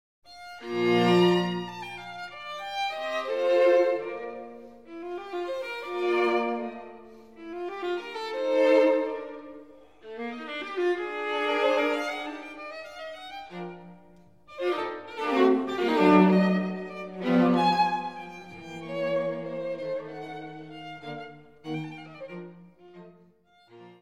für Violine, Viola und Violoncello
Fassung): Allegro moderato